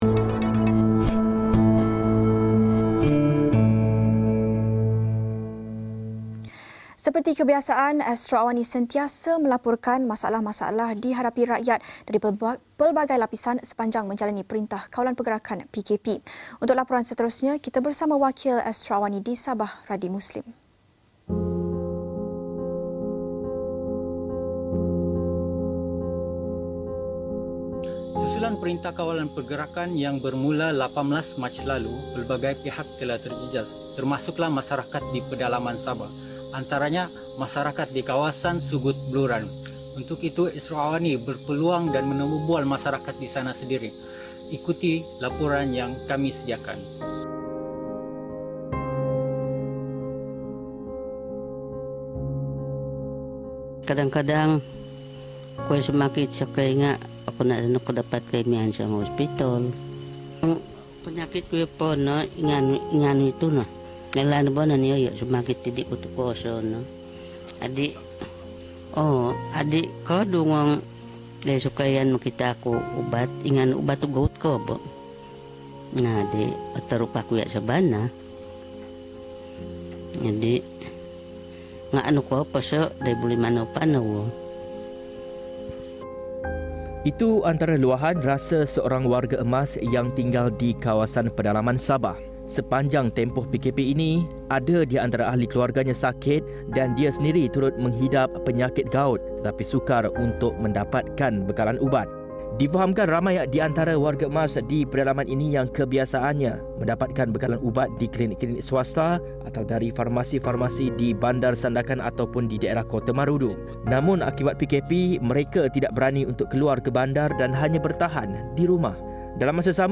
Episod ini telah disiarkan secara langsung dalam program AWANI Pagi, di saluran 501, jam 8:30 pagi.